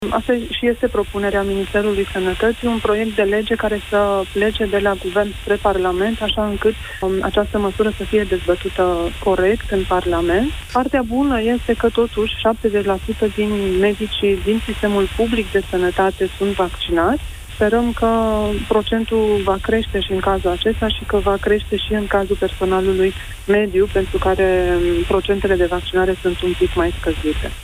Ministrul Sănătății confirmă la Europa FM informația conform căreia a renunțat la emiterea ordinului  privind testarea obligatorie, periodică, din bani proprii, la SARS COV-2, a angajaților din Sănătate care refuză vaccinarea.